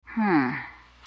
hmm1.wav